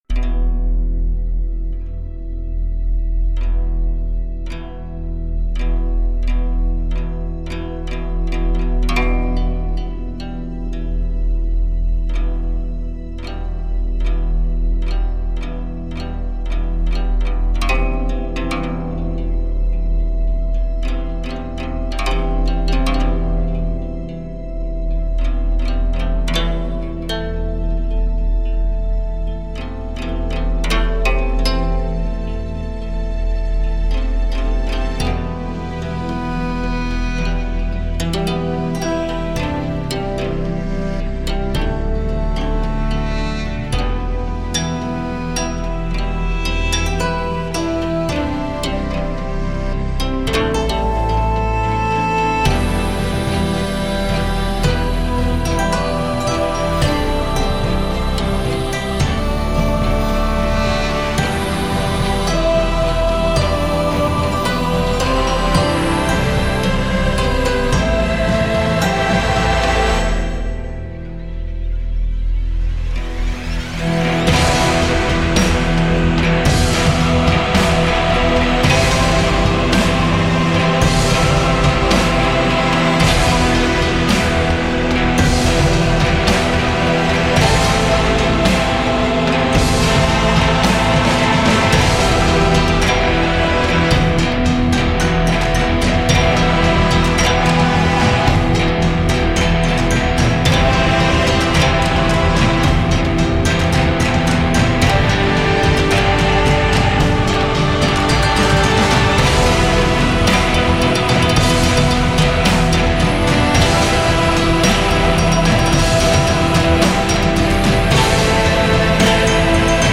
古筝是一种中国的弹拨乐器，是亚洲多种乐器的祖先，如日本的琴、蒙古的雅特加和韩国的伽倻琴。它有 21 根弦和可移动的琴码。
Wavesfactory-Guzheng.mp3